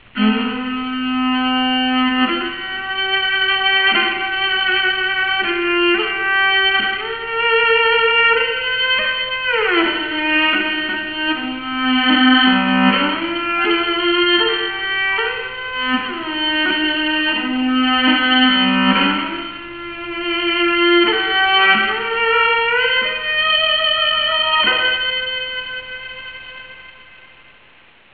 馬頭琴の魅力たっぷりのCDです。